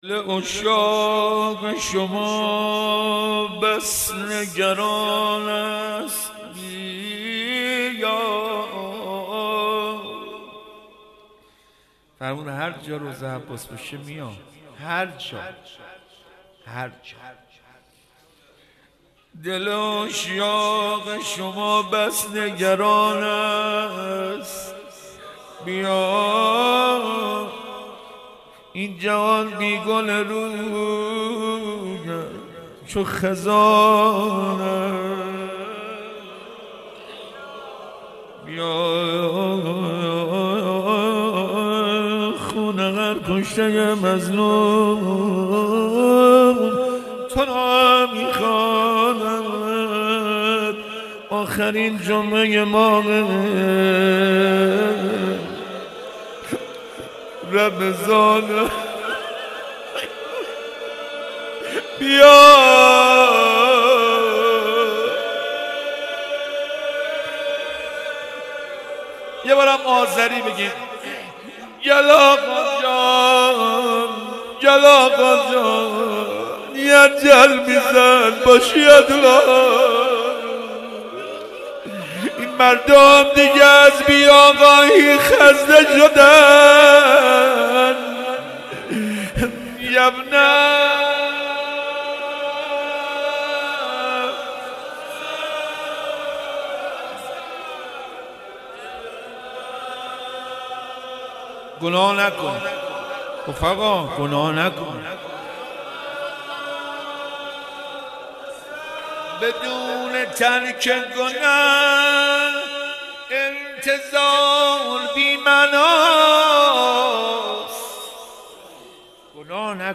مناجات با امام زمان (عج)